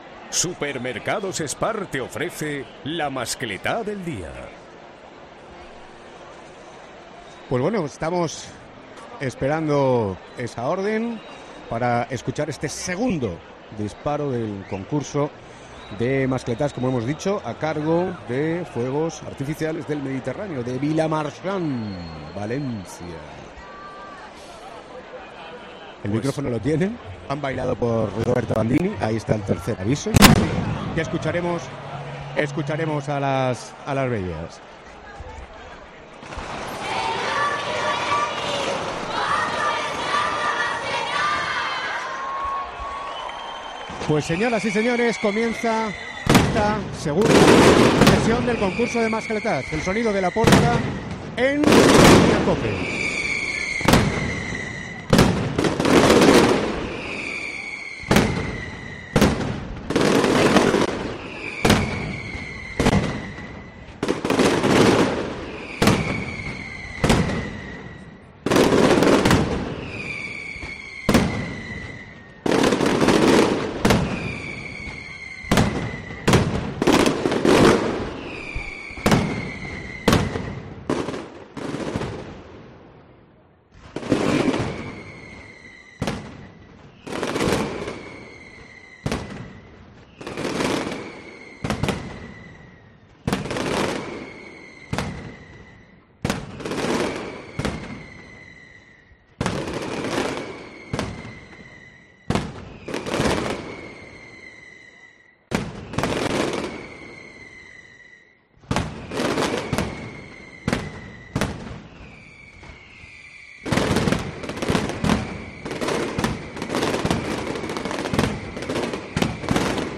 Así hemos vivido en Mediodía COPE el disparo de Fuegos Artificiales del Mediterráneo
Vuelve a escuchar aquí el sonido de la pólvora que ha hecho vibrar a alicantinos y turistas en la emblemática plaza de la ciudad.
El espectáculo de Fuegos Artificiales del Mediterráneo, con un gran terremoto final, ha quedado eliminado del Concurso de Hogueras al quedarse corta de duración por apenas 6 segundos.